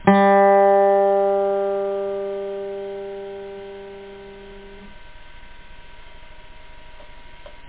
Guitar
1 channel
GSaite.mp3